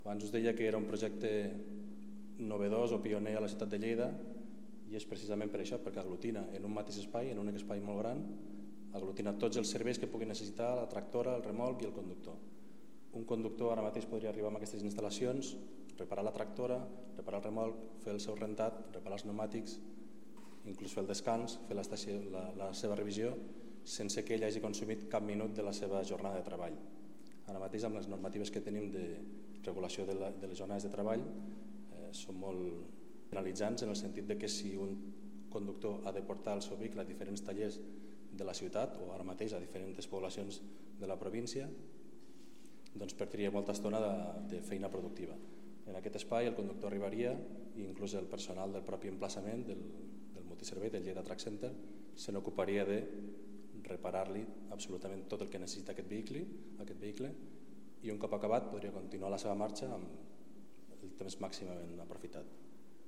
Tall de veu del paer en cap, Fèlix Larrosa, sobre el nou projecte Lleida Truck Center